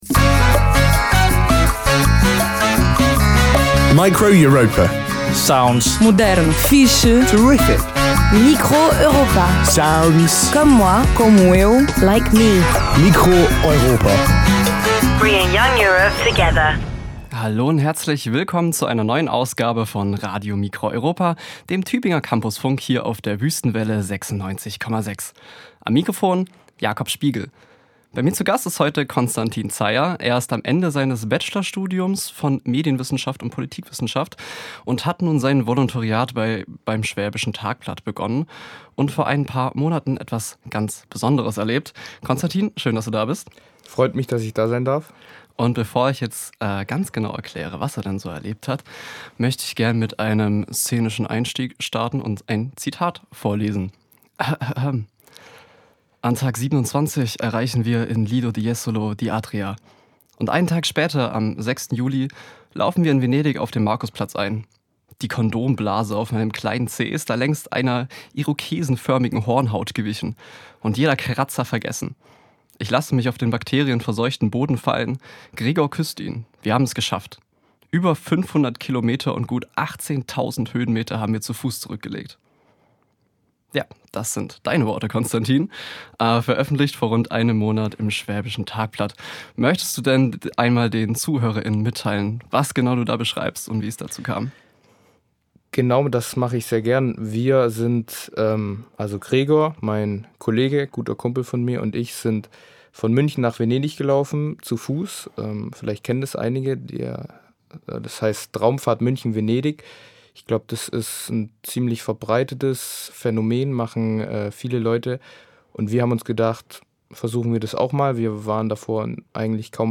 Piano und Gitarre
Bass und E-Gitarre
Drums
Form: Live-Aufzeichnung, geschnitten